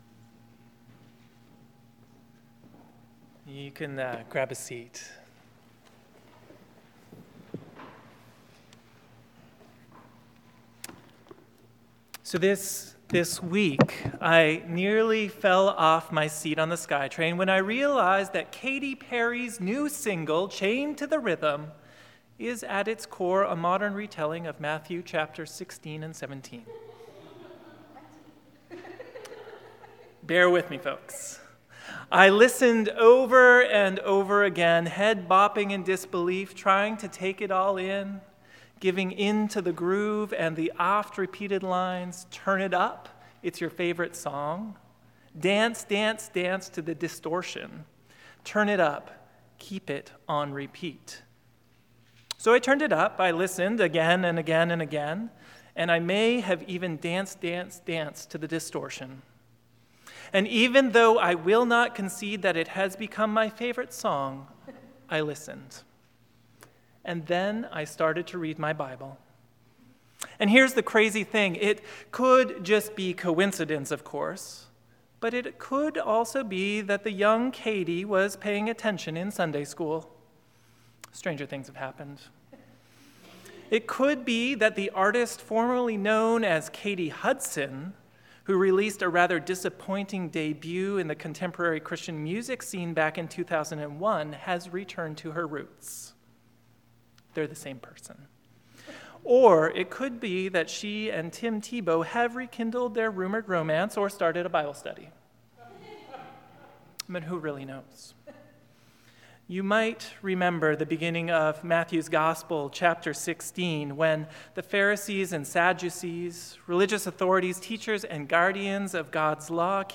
Sermons | Christ Church Cathedral Vancouver BC